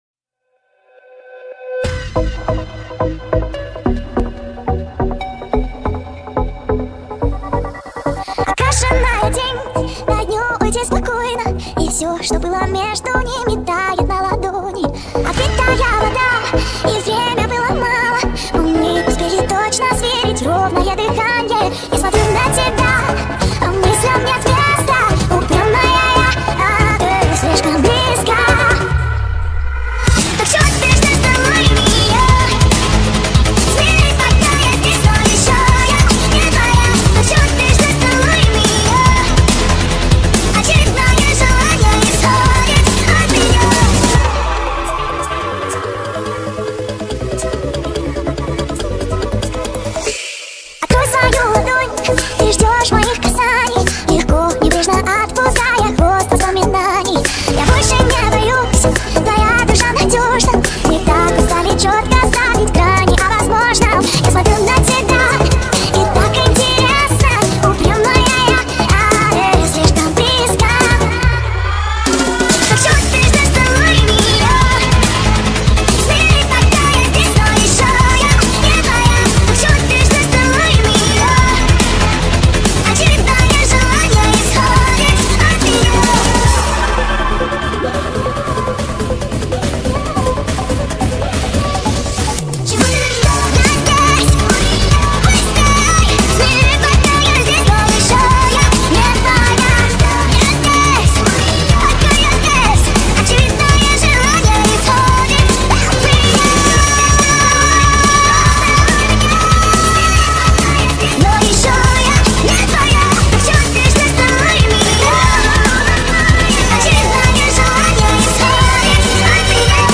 Жанр: Казахские
Tiktok remix